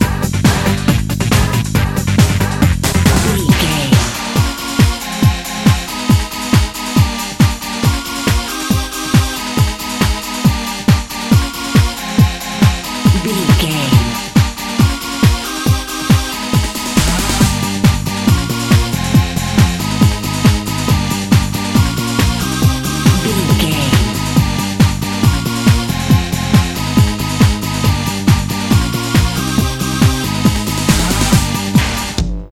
Uplifting
Aeolian/Minor
Fast
drum machine
synthesiser
electric piano
conga